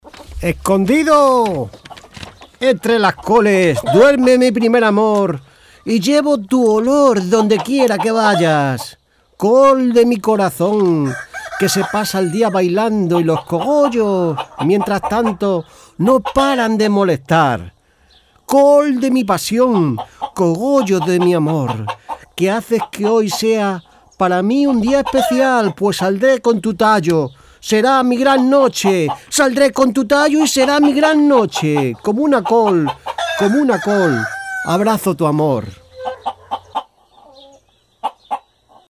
locutor, voice over
guiones-humor-coles-locutor-voiceover.mp3